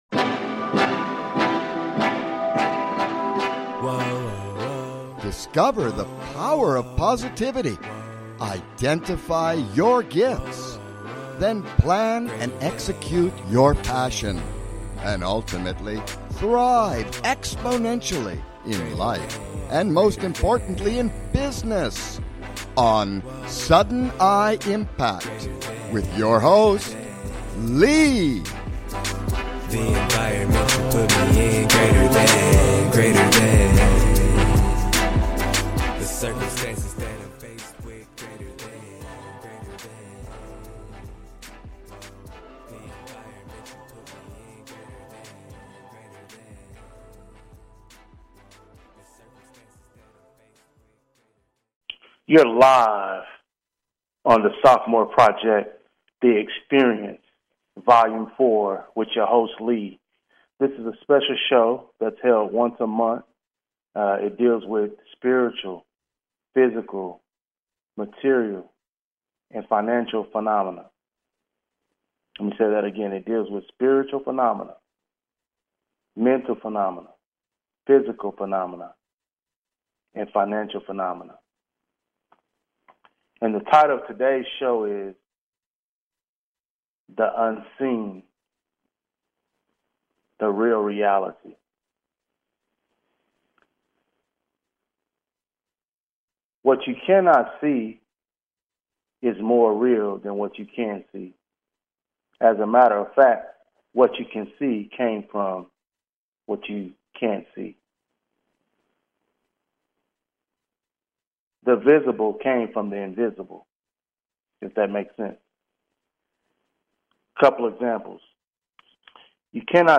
Sudden I Impact (sii) is a talk show about discovery, helping people raise their awareness and identify their gifts, finding things in life they enjoy doing, finding their uniqueness, and potentially turning their passions into businesses that thrive, and most of all, living life by plan and design to earn a living doing what they love (the essential thing).